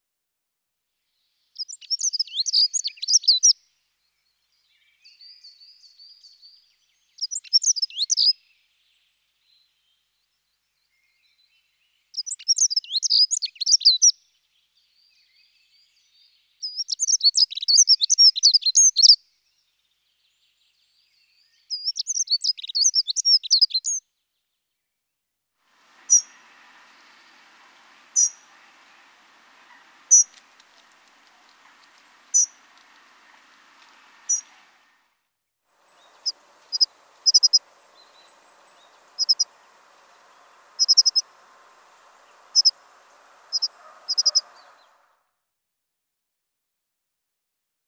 Cliquez sur lecture pour écouter le chant : Accenteur mouchet
14-Accenteur-mouchet.mp3